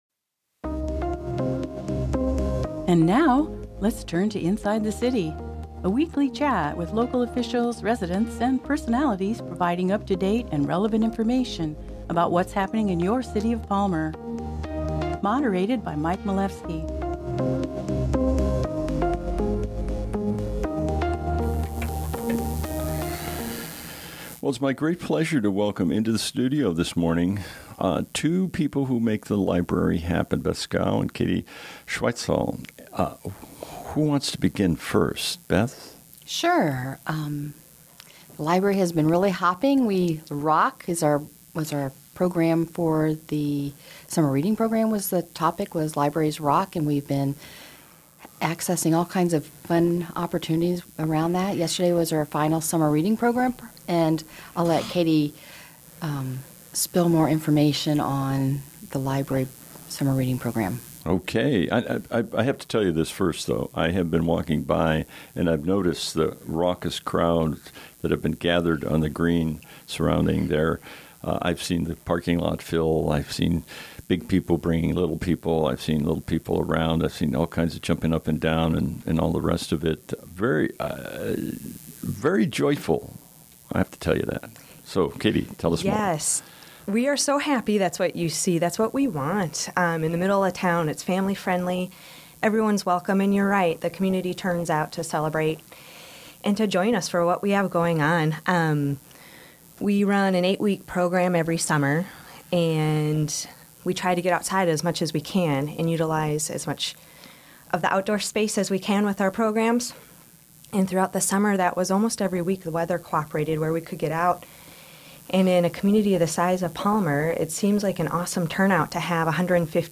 Discussions and interviews with employees and administrators from the city of Palmer